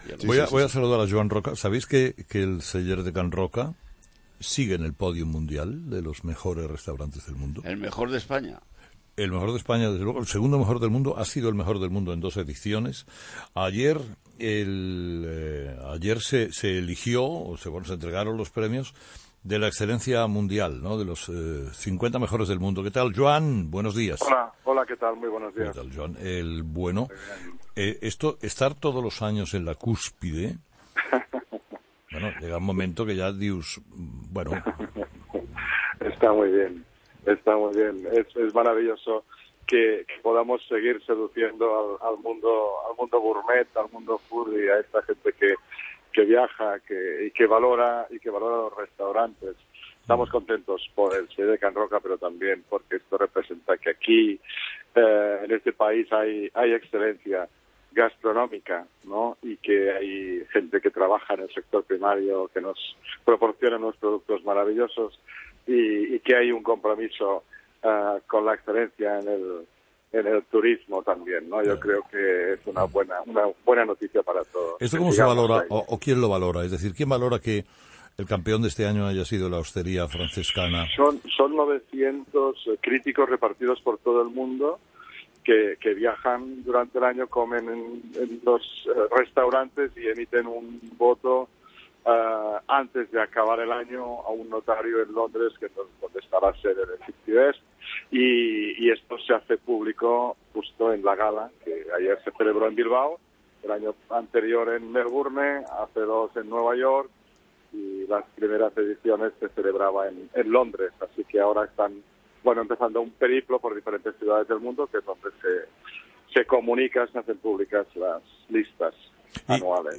Entrevista con Joan Roca